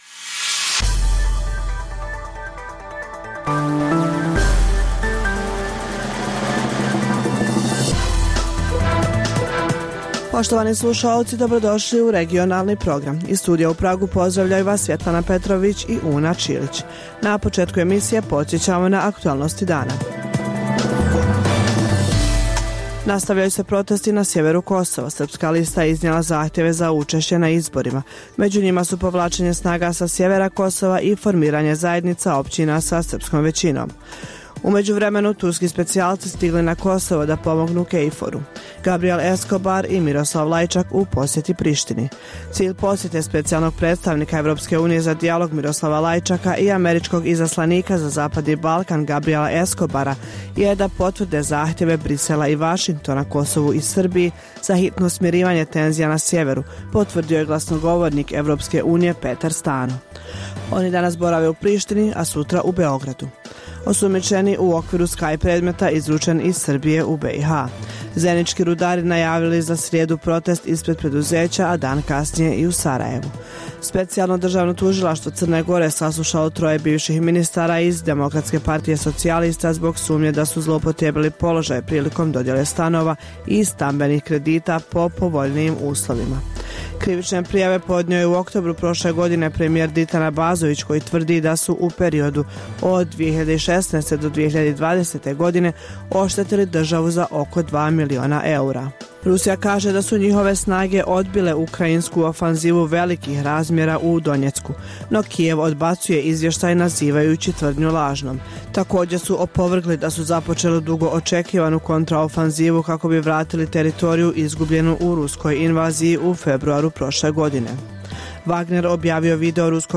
Emisija o dešavanjima u regionu (BiH, Srbija, Kosovo, Crna Gora, Hrvatska) i svijetu. Prvih pola sata emisije sadrži najaktuelnije i najzanimljivije priče o dešavanjima u zemljama regiona i u svijetu (politika, ekonomija i slično). Preostalih pola sata emisije, nazvanih “Dokumenti dana” sadrži analitičke priloge iz svih zemalja regiona i iz svih oblasti, od politike i ekonomije, do kulture i sporta.